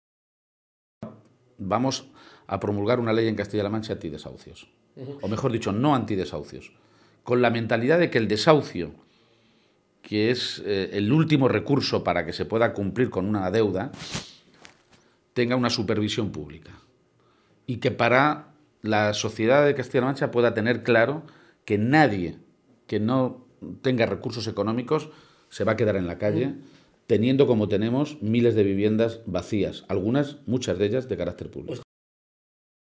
Entrevista a García-Page en la Cadena Ser